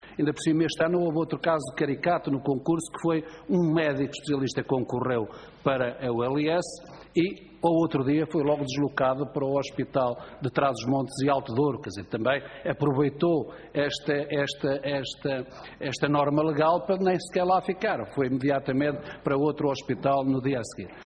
José Silvano, em declarações captadas pelo canal do Parlamento, pede um despacho semelhante ao dado ao Centro Hospitalar do Algarve, que aprove a mobilidade de profissionais para suprir eventuais carências.